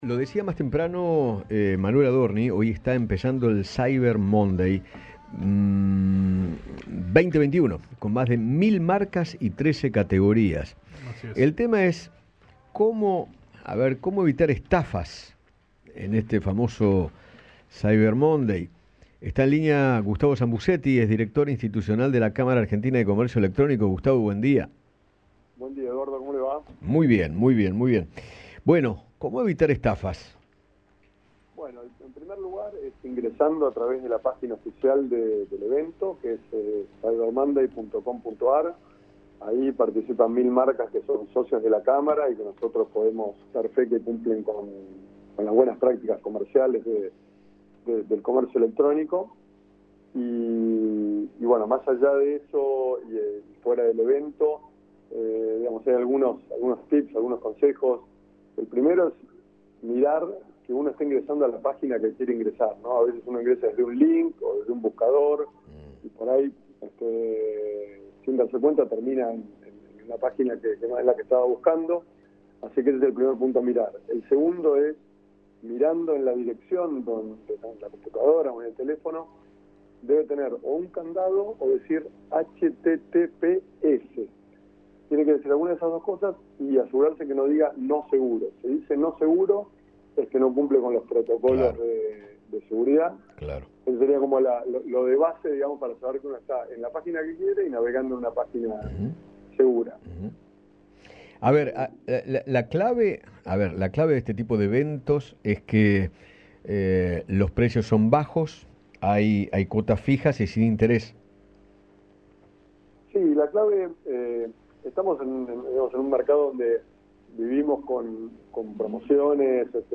Como-evitar-estafas-en-el-Cyber-Monday-Radio-Rivadavia-AM6.mp3